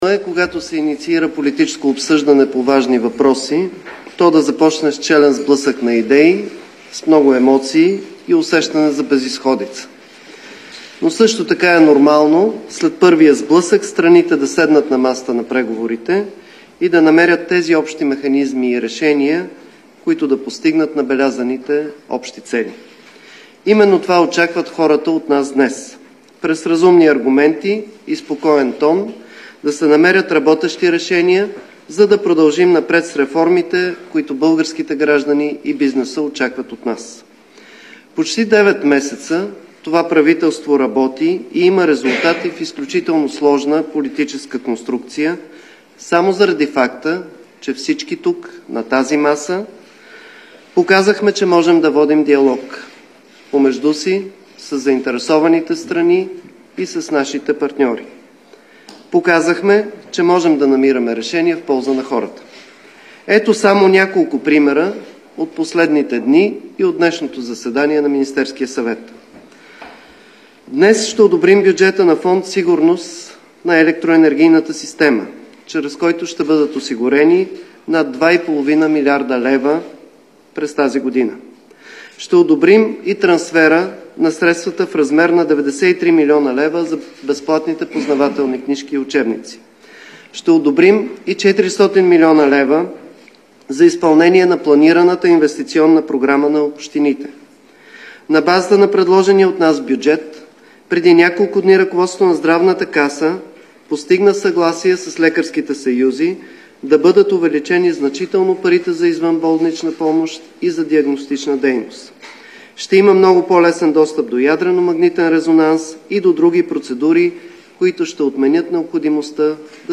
11.10 - Брифинг на общинския съветник от Възраждане Деян Николов за въпрос за бежанците и търговията с интереси в СОС.
Директно от мястото на събитието